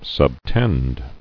[sub·tend]